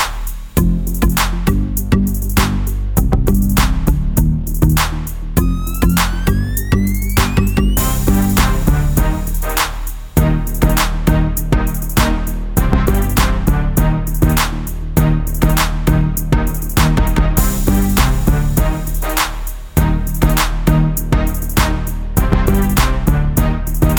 for duet R'n'B / Hip Hop 4:12 Buy £1.50